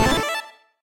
Techmino/media/effect/chiptune/spawn_5.ogg at beff0c9d991e89c7ce3d02b5f99a879a052d4d3e